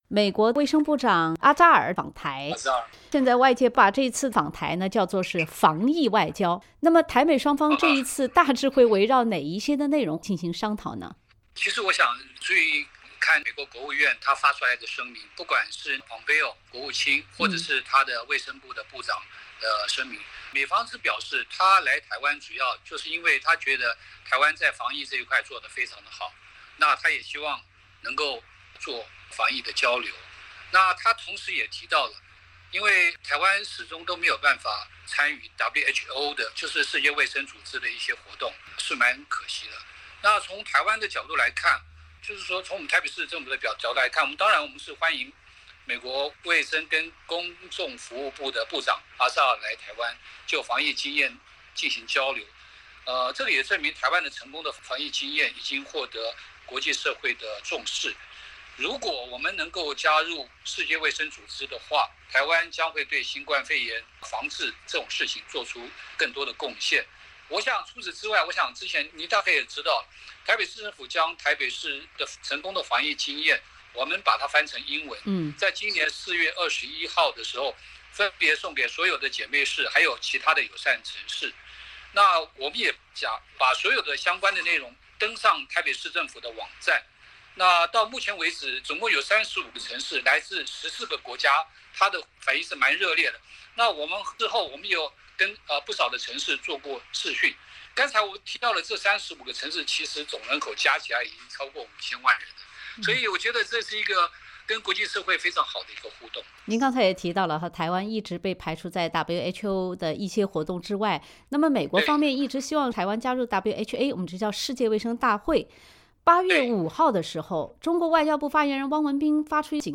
本台记者采访了前台湾驻荷兰大使，现任台北市政府国际事务委员会副主任委员周台竹先生。
欢迎收听本台记者对台北市政府国际事务委员会副主任委员周台竹先生的采访。